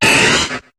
Cri de Papinox dans Pokémon HOME.